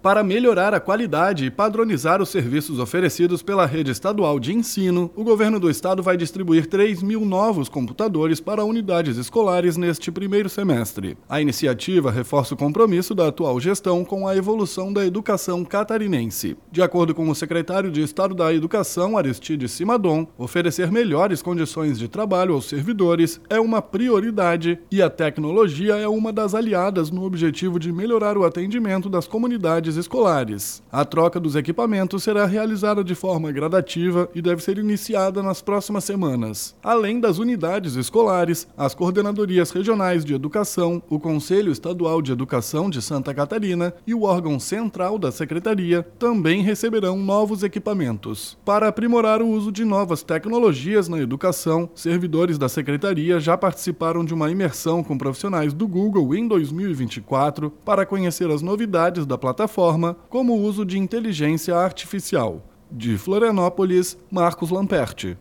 BOLETIM – Governo do Estado entrega computadores às escolas estaduais para qualificar e padronizar serviços